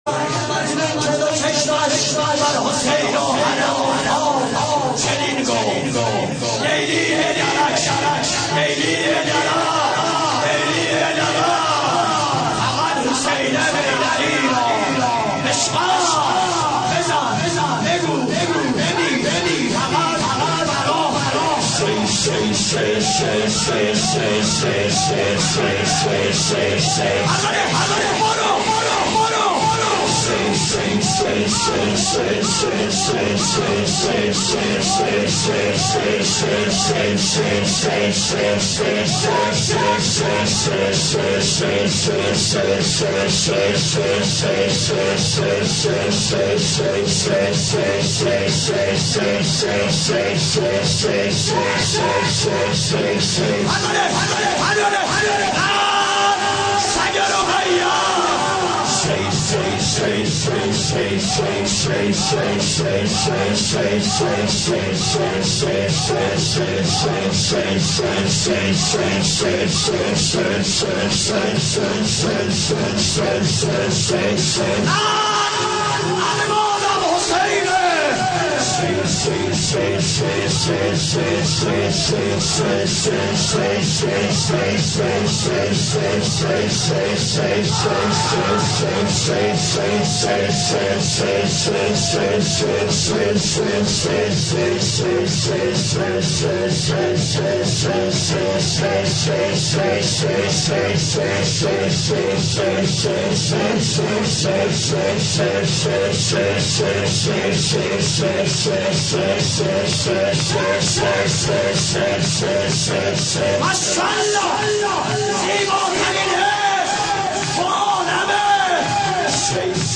ذکرگویی